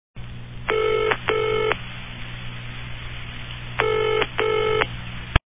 /32kbps) 16kbps (10.7кб) Описание: Гудки в телефоне ID 24942 Просмотрен 6555 раз Скачан 1880 раз Скопируй ссылку и скачай Fget-ом в течение 1-2 дней!